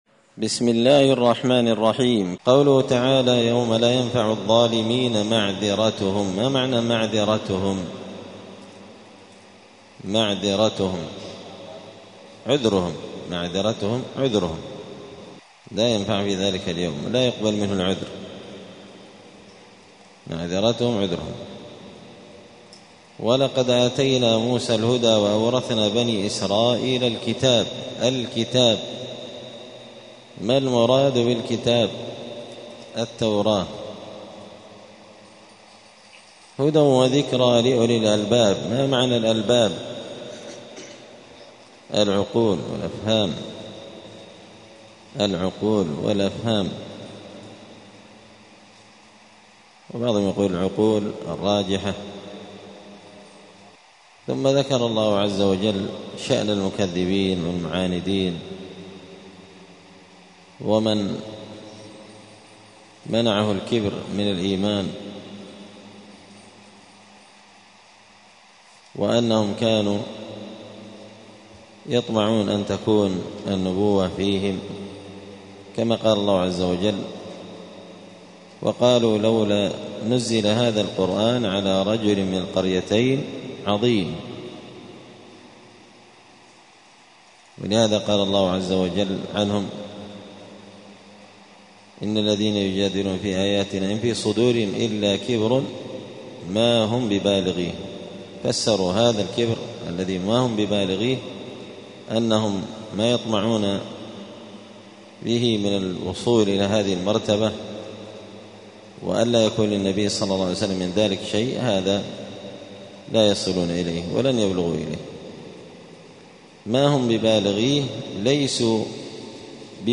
زبدة الأقوال في غريب كلام المتعال الدرس التاسع والستون بعد المائتين (269)